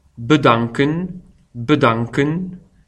bedanken.mp3